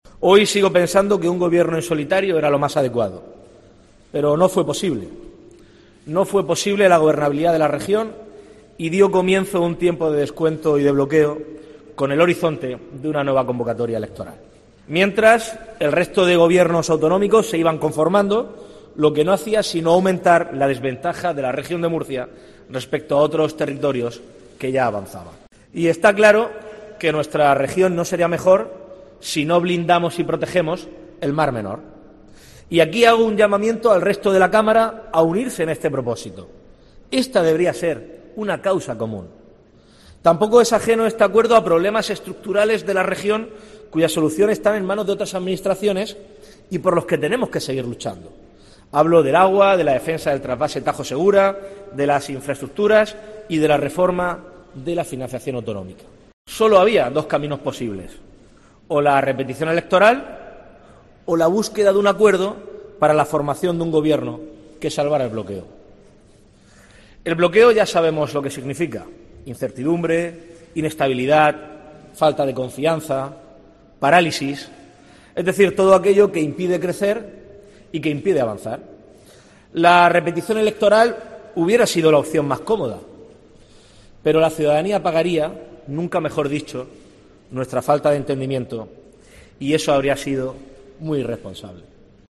DEBATE DE INVESTIDURA